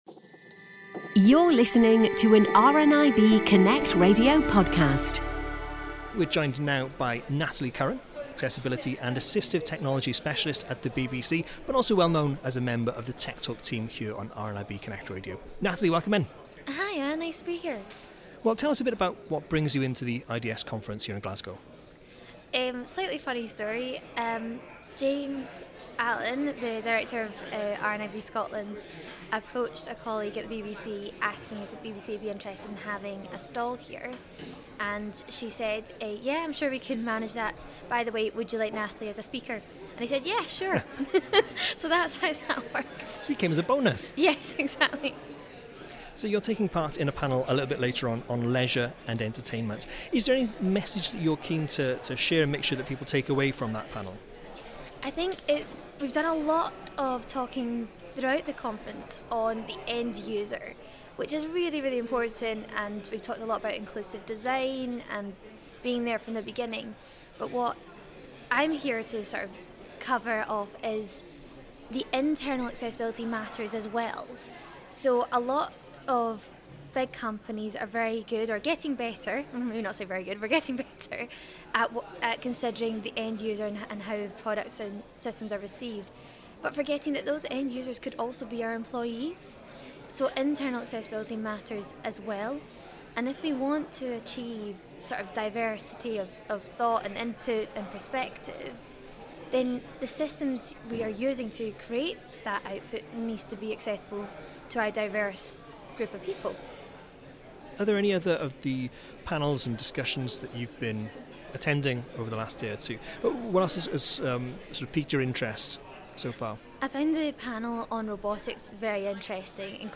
On Tuesday 18th of March, The Lunch Break was broadcasting live from the Inclusive Design for Sustainability Conference in Glasgow.